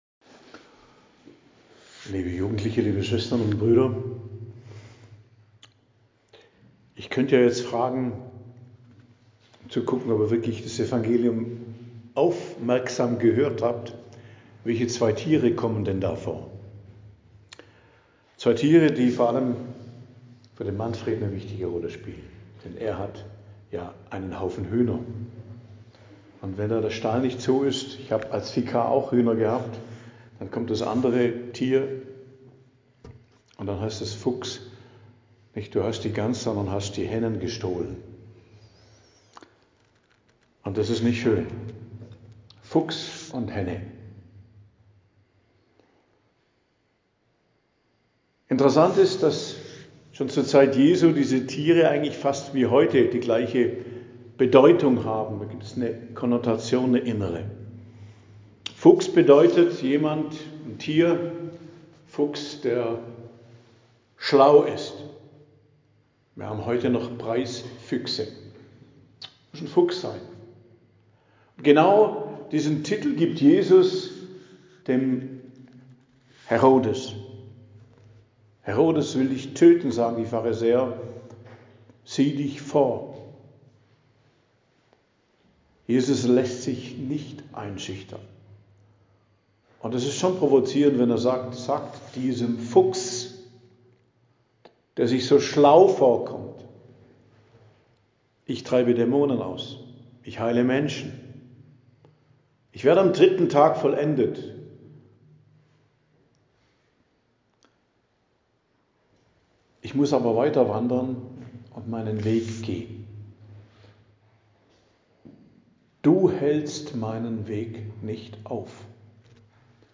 Predigt am Donnerstag der 30. Woche i.J., 30.10.2025